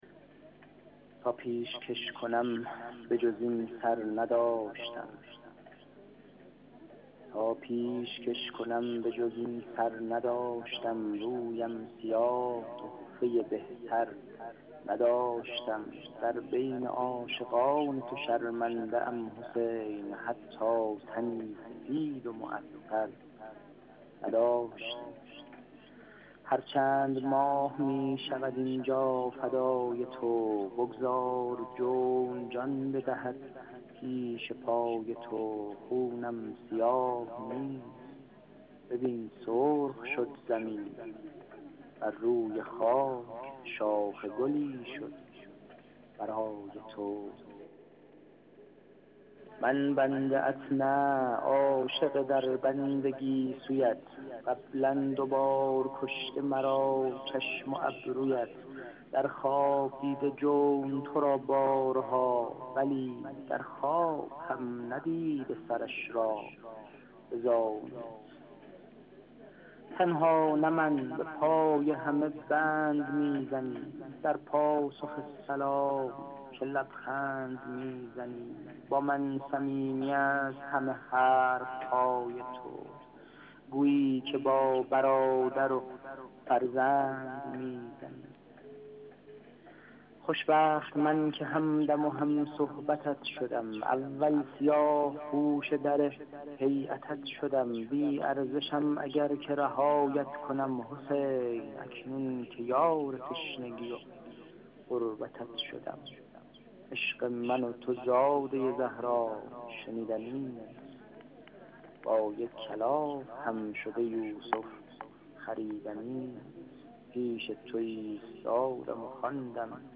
فایل صوتی شعرخوانی این شاعر